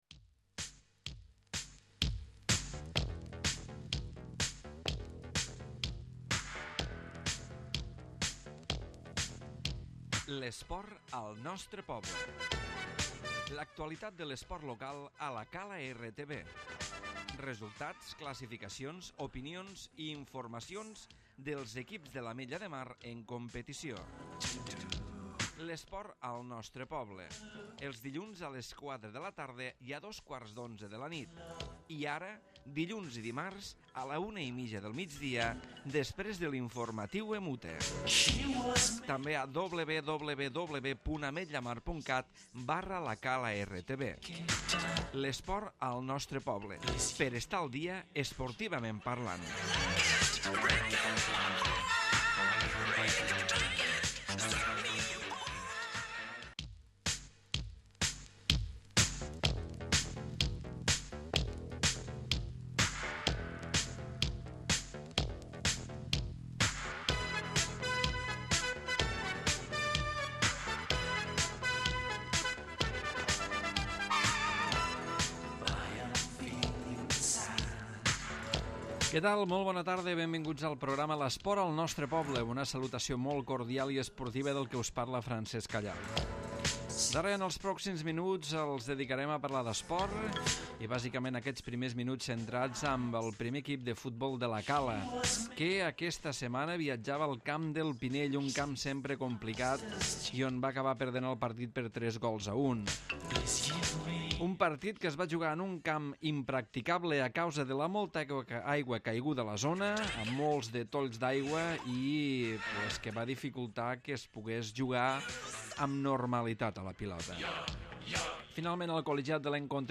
Programa esportiu amb informacions de les activitats esportives locals.